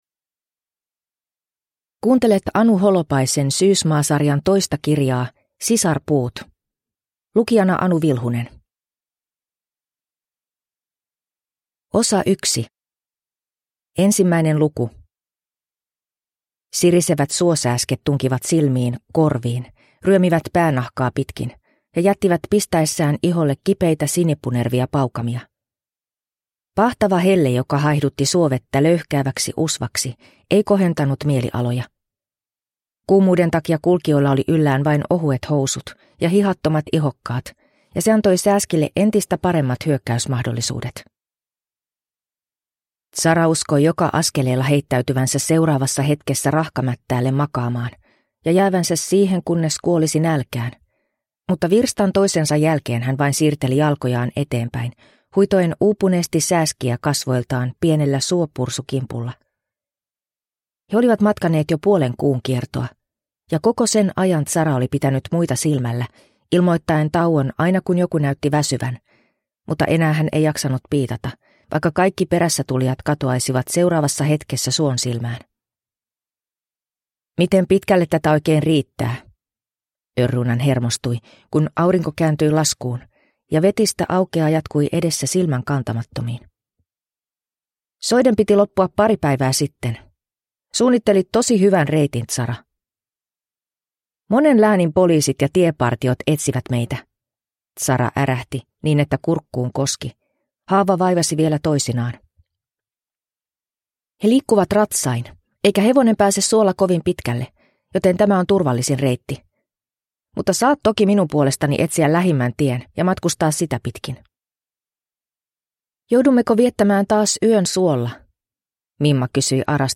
Sisarpuut – Ljudbok